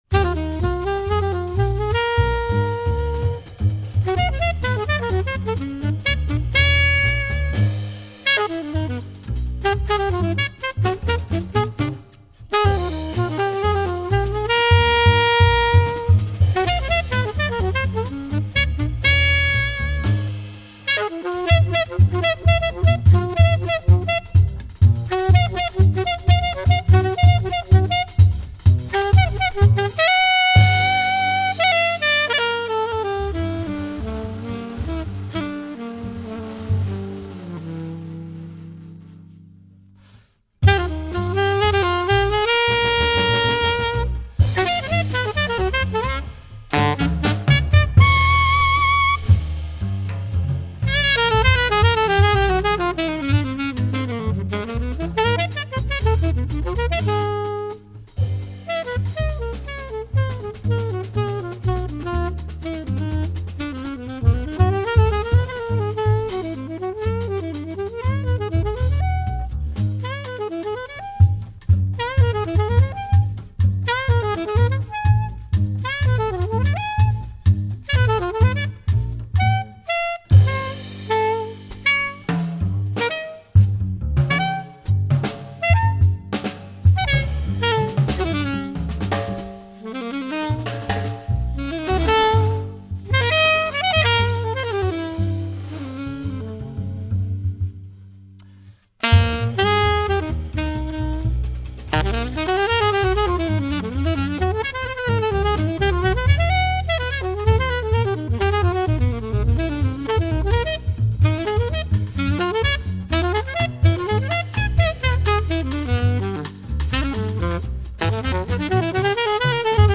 Genre: Jazz.